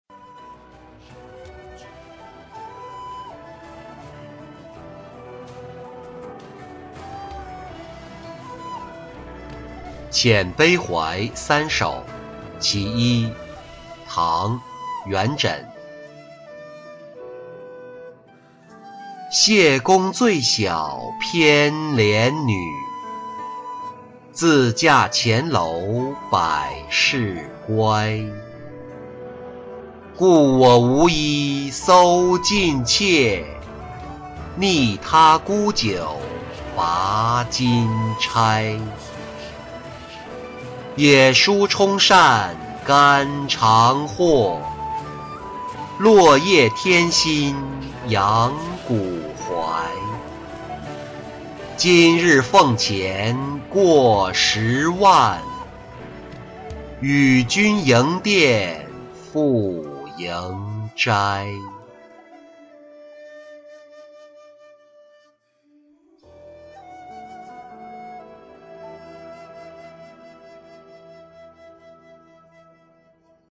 遣悲怀三首·其一-音频朗读